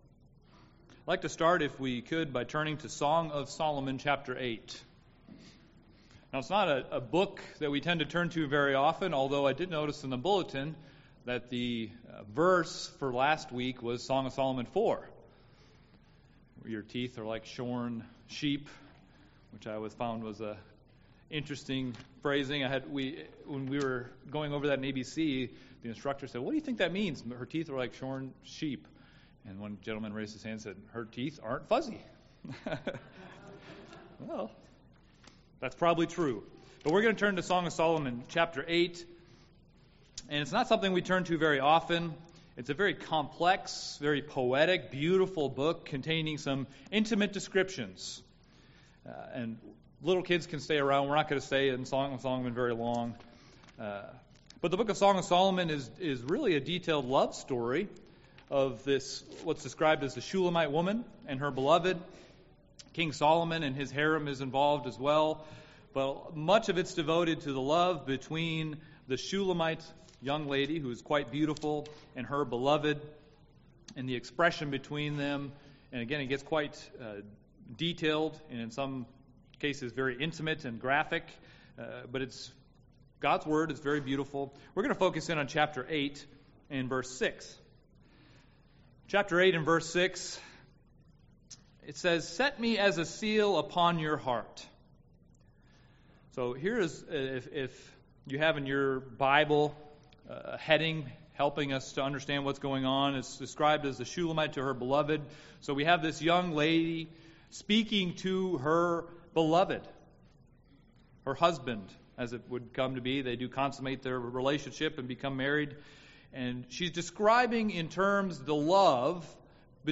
Given in Milwaukee, WI
If we are cognizant of that tendency, we can take steps to avoid losing that precious aspect of our lives. first love love desire passion zeal zealous UCG Sermon Studying the bible?